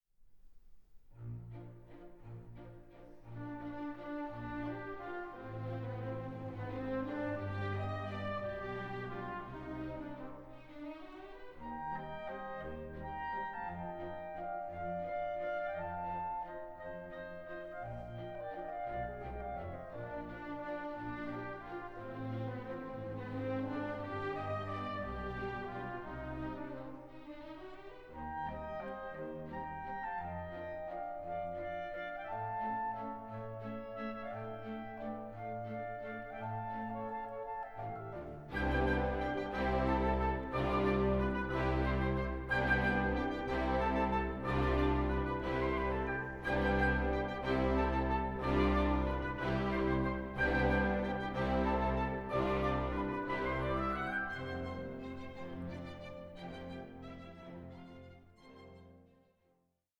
ballet in four acts